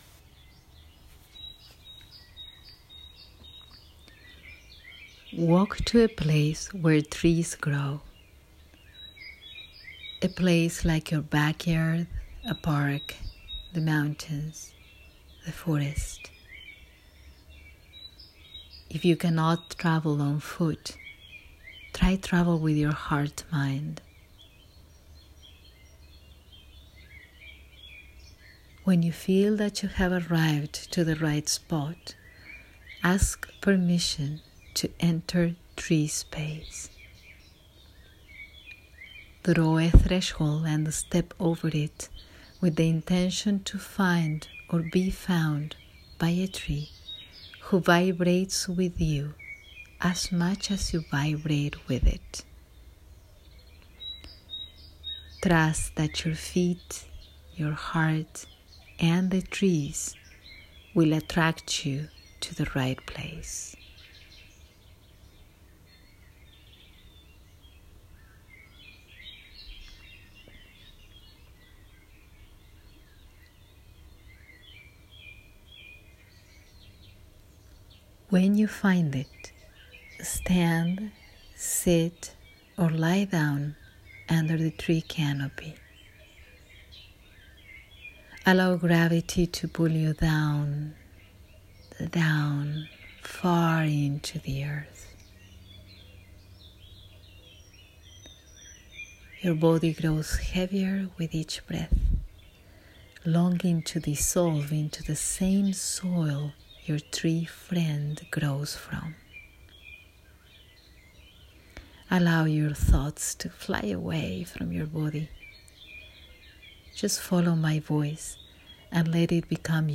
Being Tree guided meditation